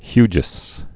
(hyjəs)